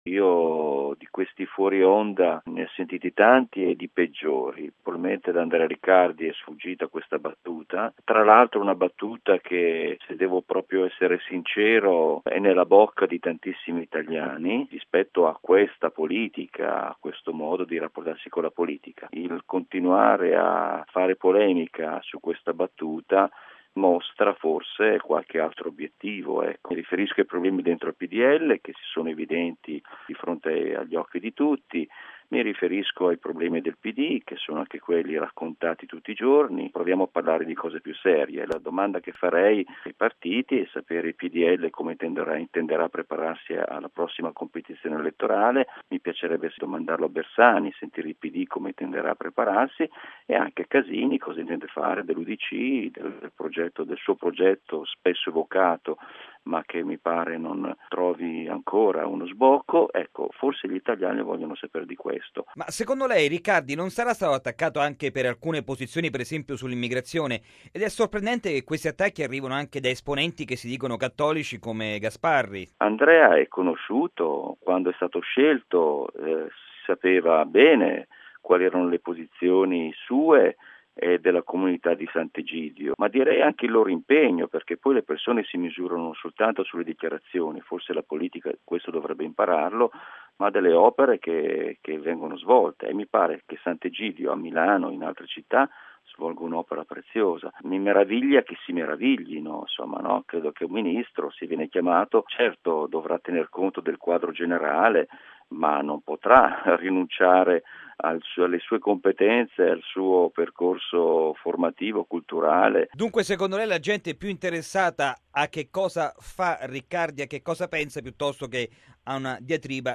Radiogiornale del 09/03/2012 - Radio Vaticana